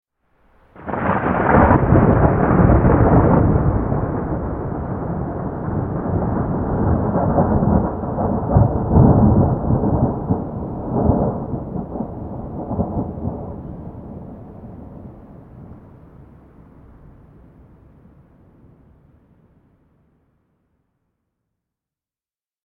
جلوه های صوتی
دانلود آهنگ رعدو برق 22 از افکت صوتی طبیعت و محیط
دانلود صدای رعدو برق 22 از ساعد نیوز با لینک مستقیم و کیفیت بالا